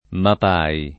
vai all'elenco alfabetico delle voci ingrandisci il carattere 100% rimpicciolisci il carattere stampa invia tramite posta elettronica codividi su Facebook MAPAI [ map # i ] n. pr. m. — sigla (femm. in ebr.) di un partito israeliano